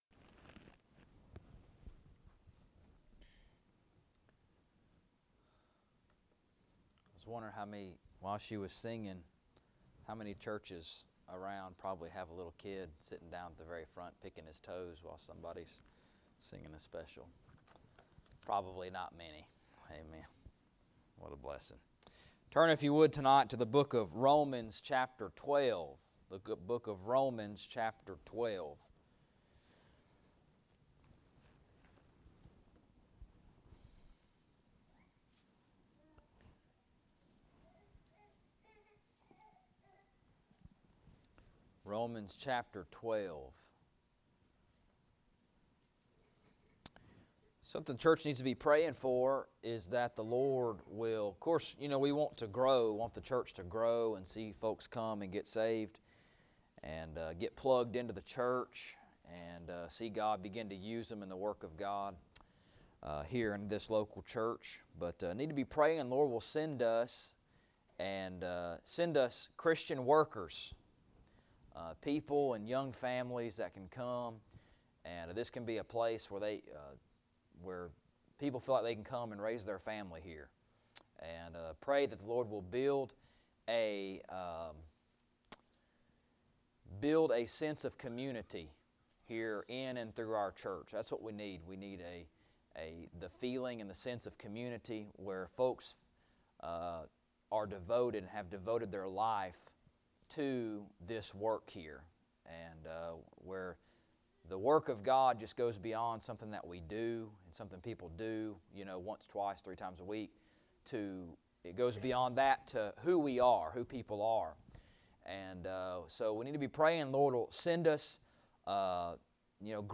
Romans 12:9 Service Type: Sunday Evening Bible Text